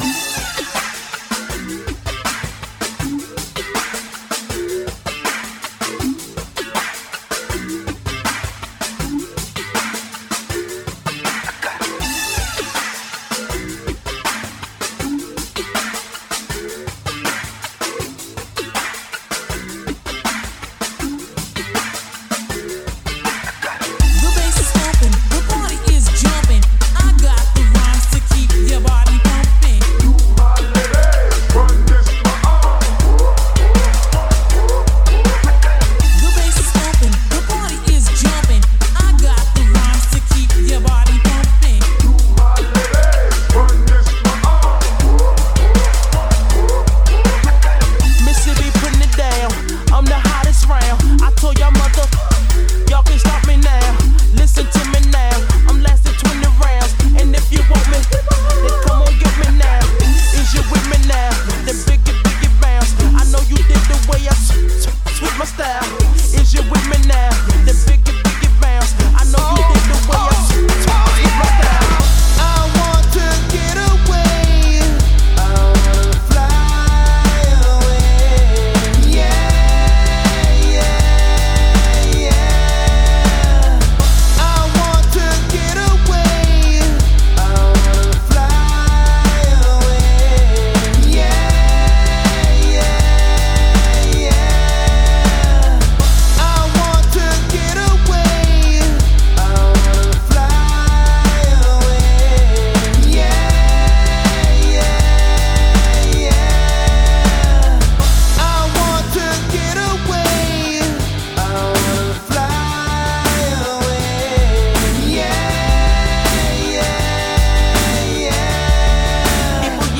a mashup track
Great mashup for ya!
Posted in Breakbeat